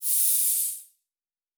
pgs/Assets/Audio/Sci-Fi Sounds/MISC/Air Hiss 2_04.wav at master
Air Hiss 2_04.wav